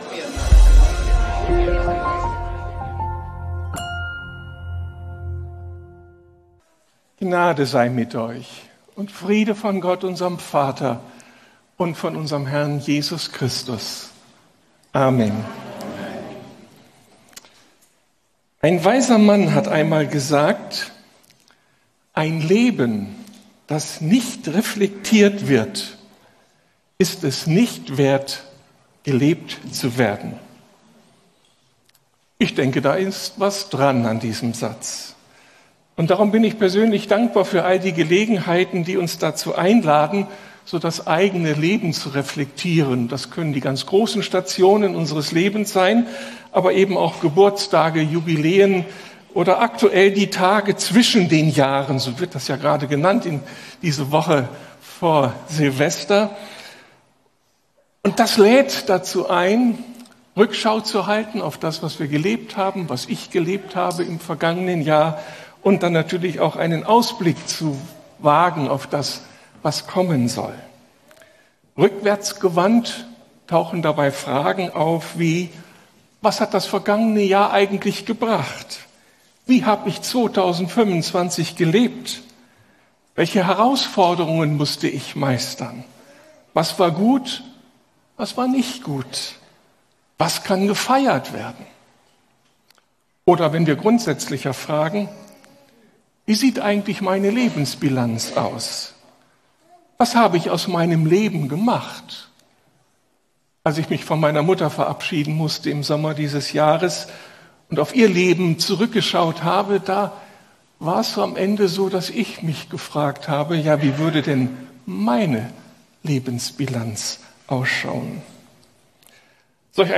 Eine neue Perspektive ~ Predigten der LUKAS GEMEINDE Podcast